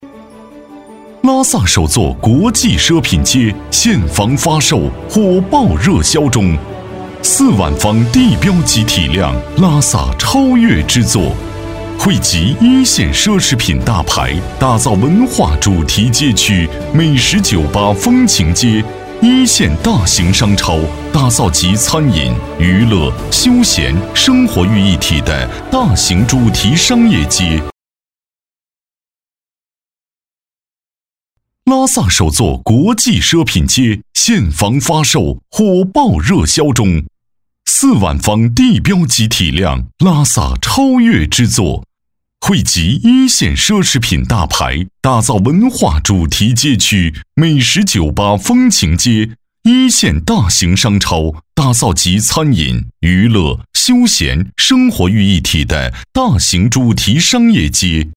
【激昂大气】-地产宣传片